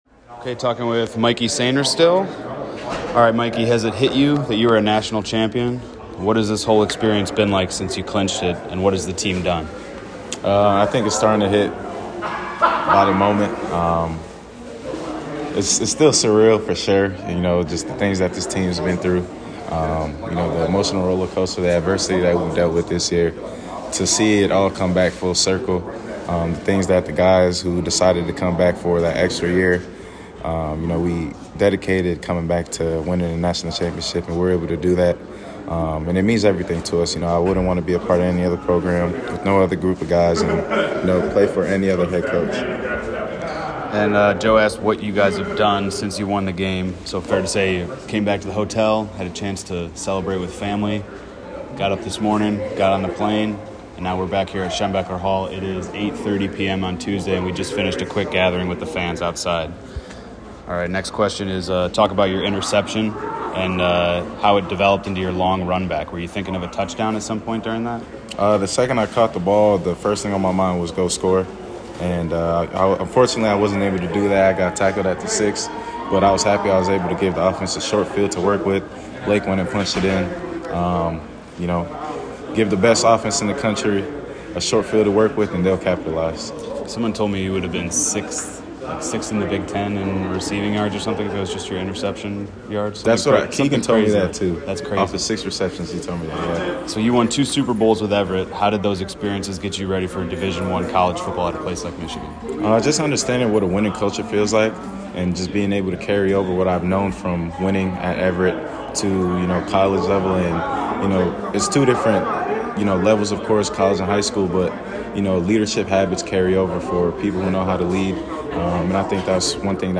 Everett native and Univ. of Michigan NCAA Champion Mike Sainristil answers questions